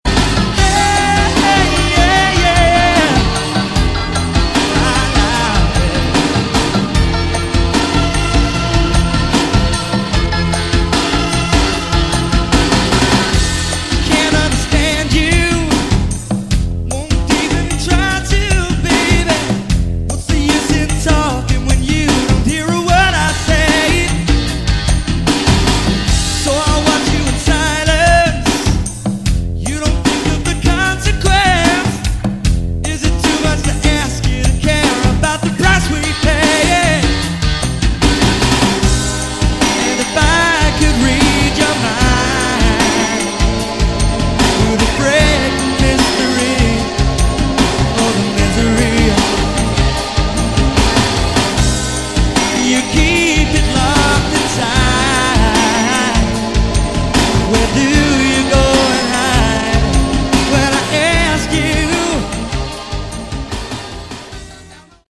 Category: Melodic Rock
Second CD consists of unreleased demos.